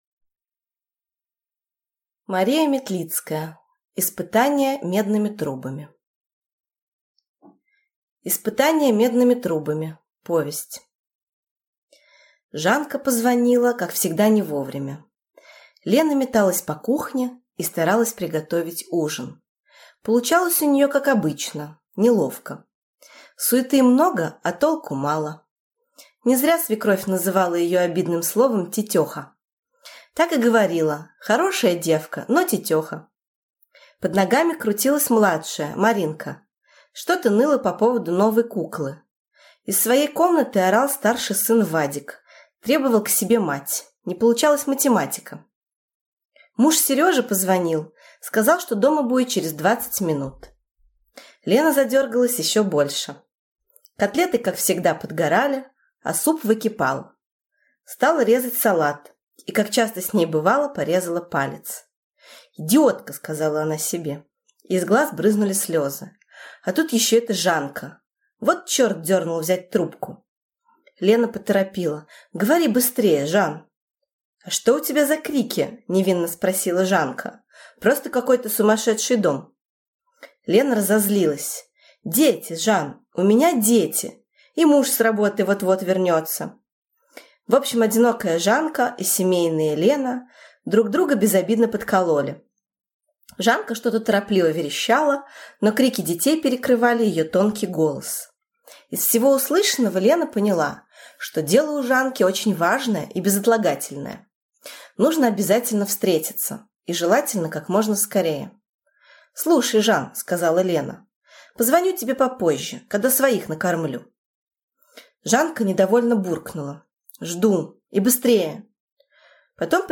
Аудиокнига Испытание медными трубами (сборник) | Библиотека аудиокниг